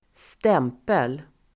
Uttal: [²st'em:pel]